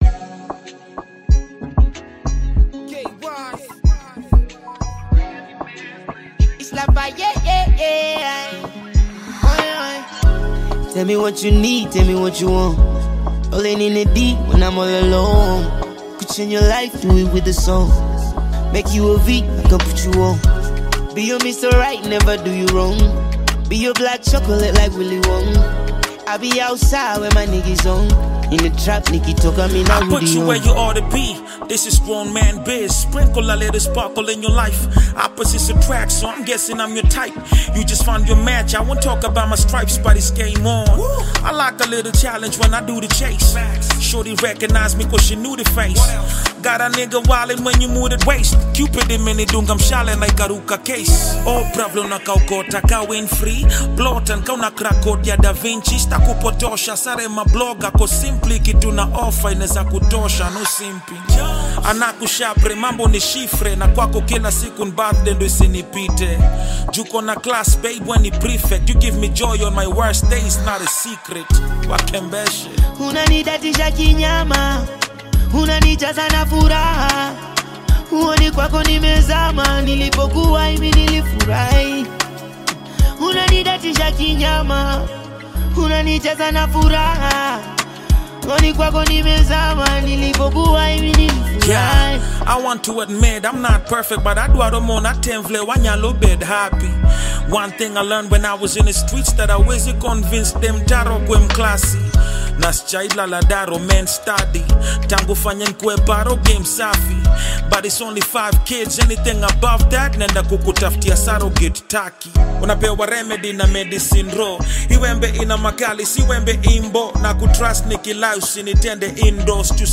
AUDIOKENYAN SONG